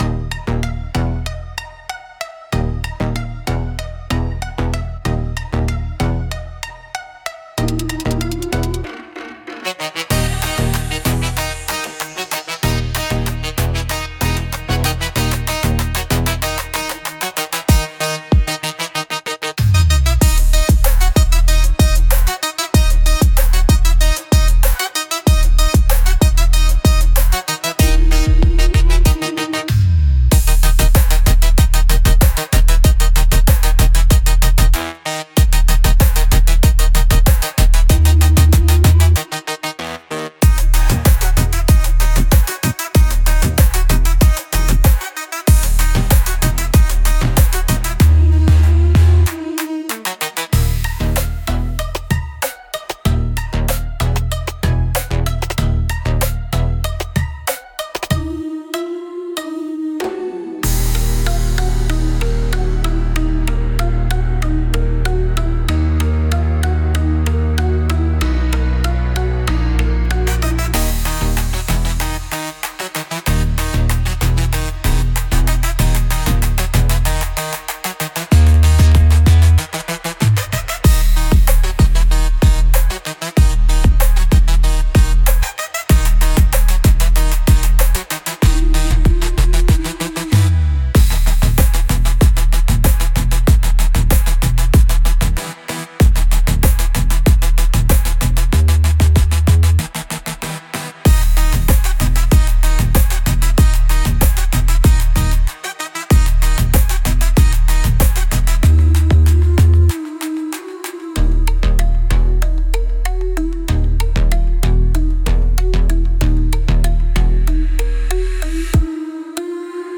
Instrumental -RUN from Those who Censor - Real Liberty Media dot XYZ-3.53